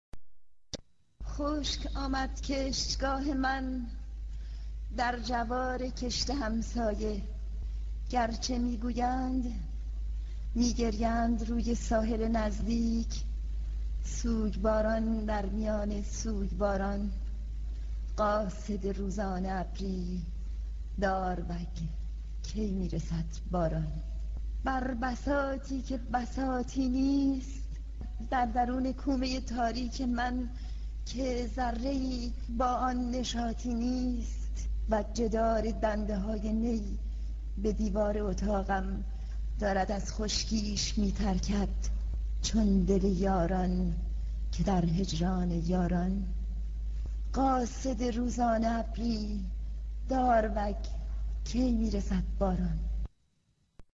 داروگ (با صدای غزاله علیزاده)
صوت داروگ (با صدای غزاله علیزاده) از شاعر نیما یوشیج در نشریه وزن دنیا